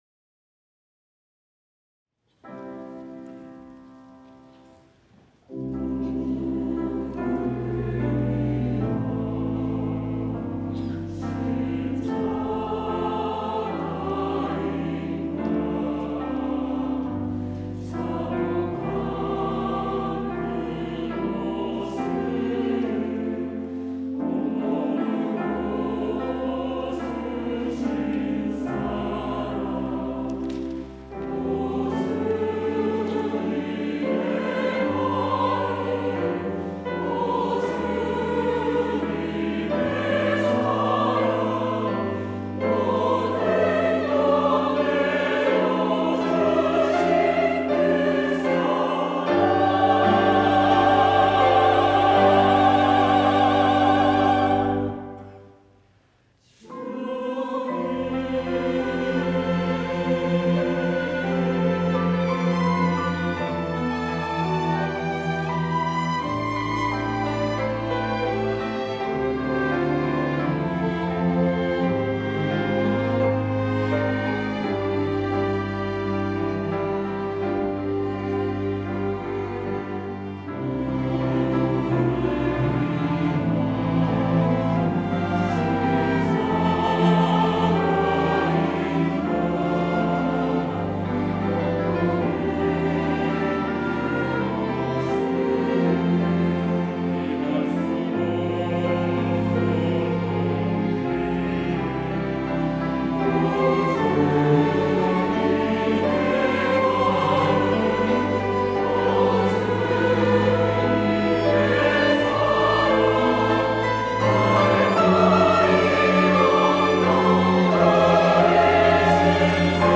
호산나(주일3부) - 누구를 위한 십자가인가
찬양대